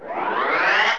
RocketPowerup.wav